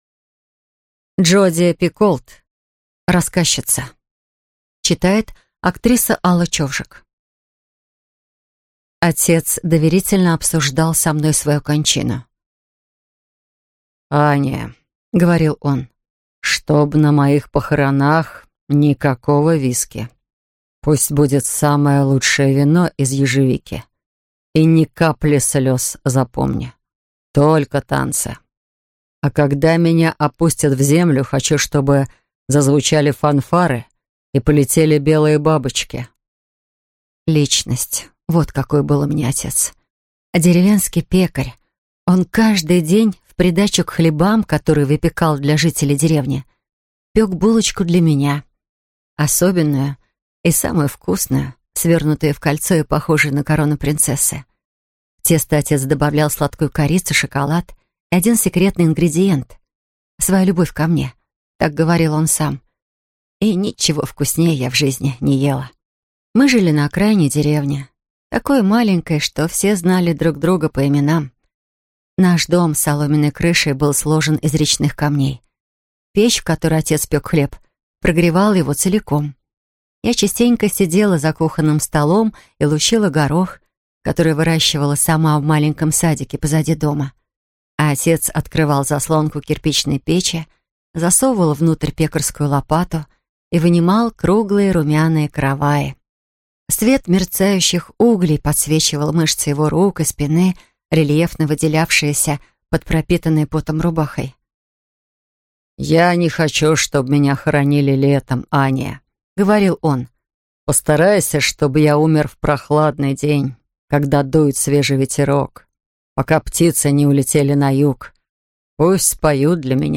Аудиокнига Рассказчица | Библиотека аудиокниг
Прослушать и бесплатно скачать фрагмент аудиокниги